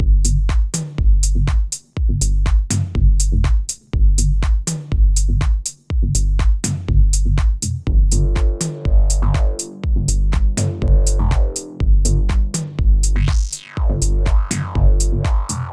Bass Station II
ну вот первый бас который звучит довольно не плохо записал немного разные между собой звуки и развел в стороны + риперовский сатурейшн, ну и пространственная обработка